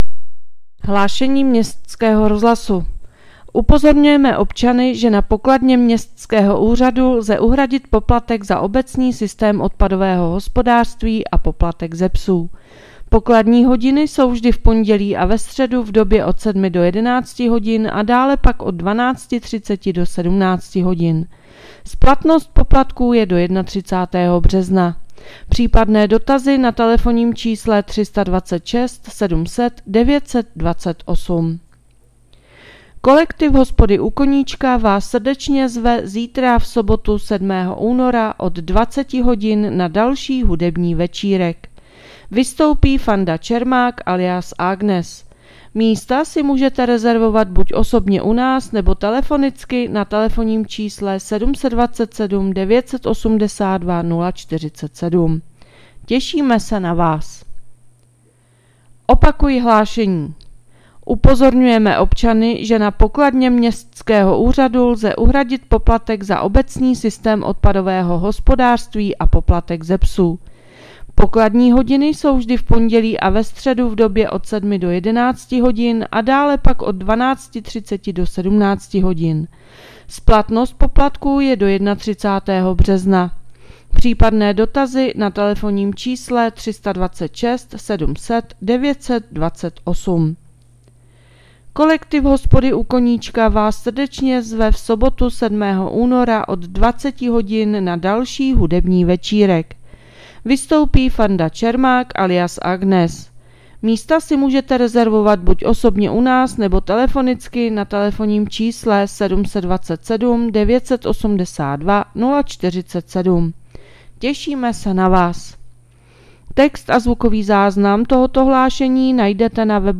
Hlášení městského rozhlasu6.2.2026